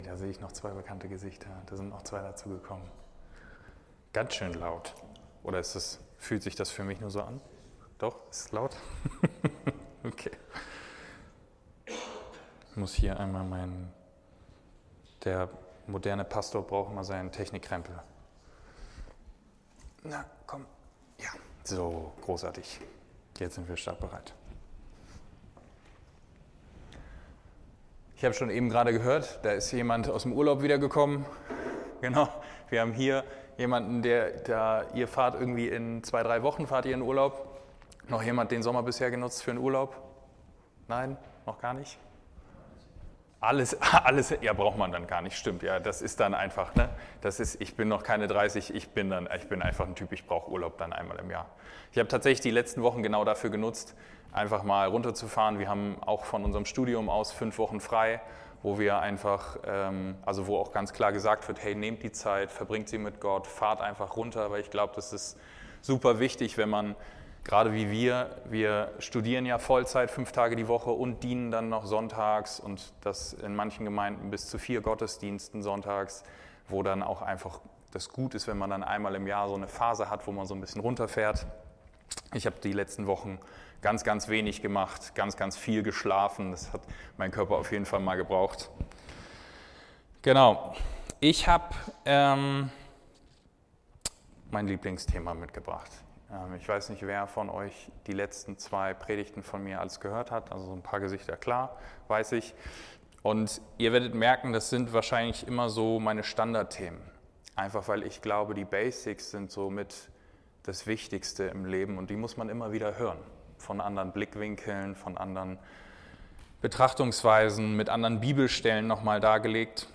Tim. 3:16+17; Jak. 5:19-20; Gal. 6:1+2 Dienstart: Predigt Warum brauchen wir Korrektur und wer darf uns wie korrigieren?